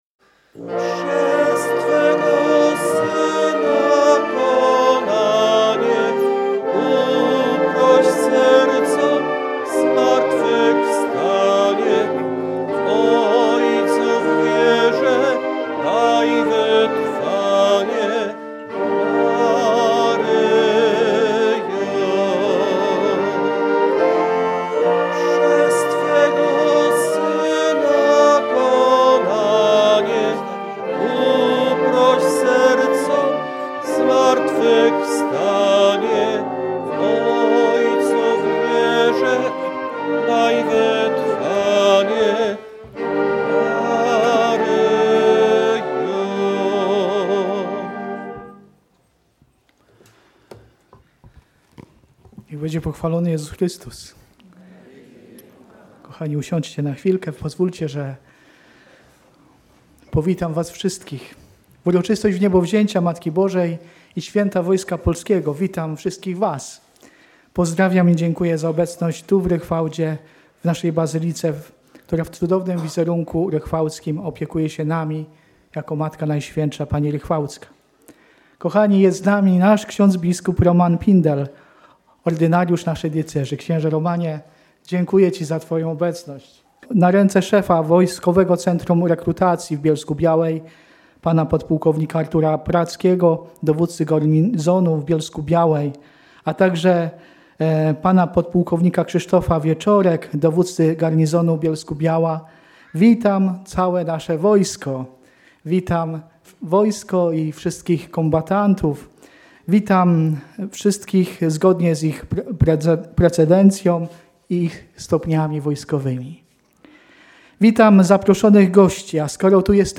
Biskup bielsko-żywiecki przewodniczył polowej Mszy św. w uroczystość maryjną.